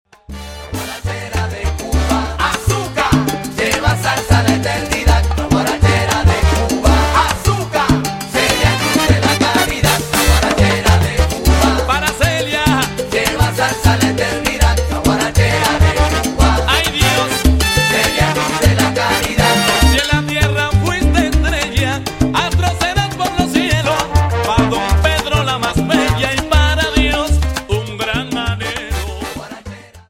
Salsa Charts - November 2008